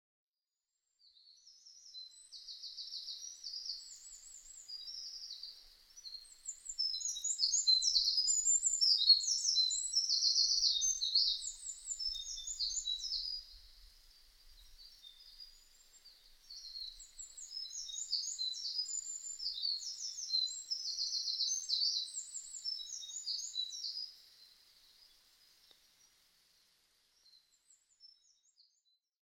ミソサザイ　Troglodytes troglodytesミソサザイ科
日光市稲荷川上流　alt=810m
Mic: Sound Professionals SP-TFB-2  Binaural Souce
左右で二羽が鳴き合っています。